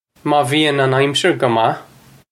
Maw vee-un un ime-sher guh mah!
This is an approximate phonetic pronunciation of the phrase.